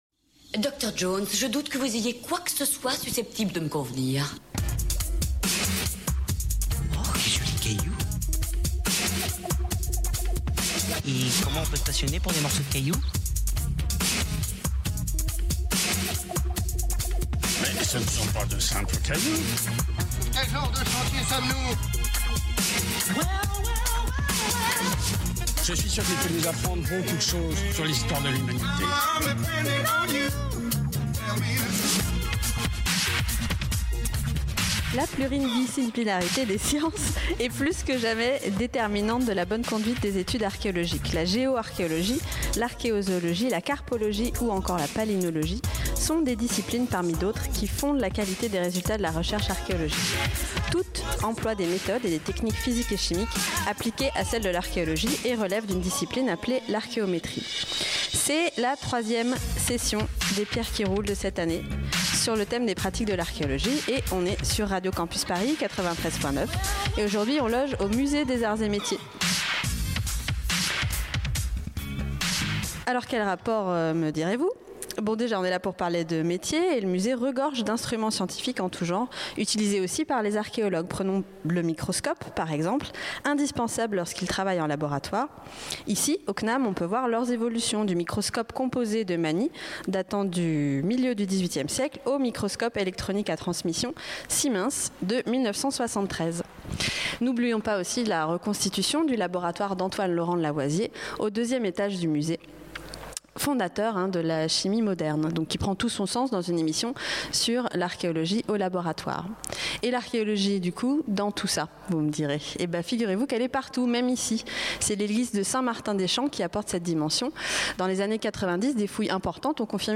On remonte peu à peu le fil pour s'intéresser aux métiers en blouse blanche de l'archéologie, ceux qui touchent aux microscopes, aux statistiques et tableaux excel en tout genre, et qui permettent la pluridisciplinarité et l'inter-connectivité des sciences. Et c'est le Musée des Arts et Métiers qui nous accueille au sein de ses murs et de ses collections, qui font écho à notre discours !